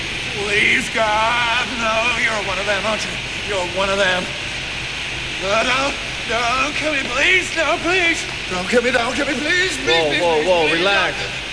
Hans faking American accent (248 kb)